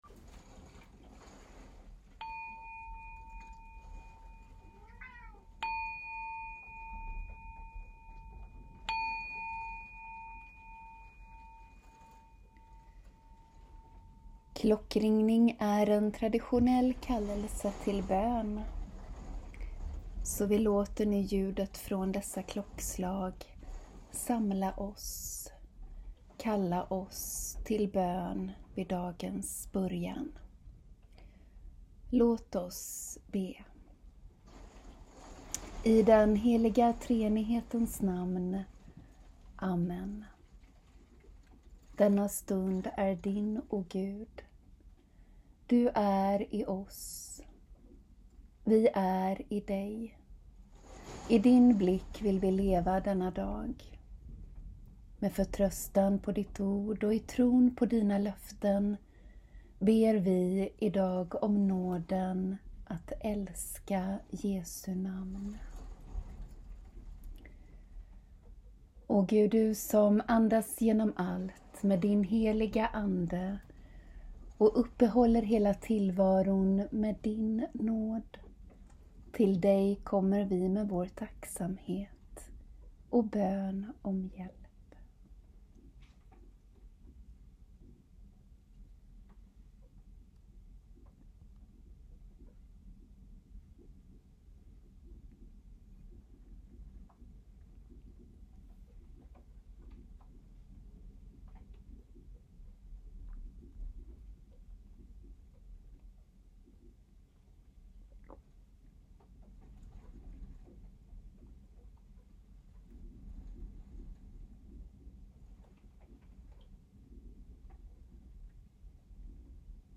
Bön vid dagens början med en längre tystnad för personlig bön och med avslutande musik What A Beautiful Name av Hillsong Worship, Brooke Ligertwood, (7 min).
Ljudfilen är inspelad i en stuga vid havet en morgon när det stormade och snöregnet vräkte ner. Det hörs också i bönen och kanske kan det få påminna om vad psalmisten skriver i Psaltaren 147 om att både snö och hagel, storm och köld ska prisa Herren.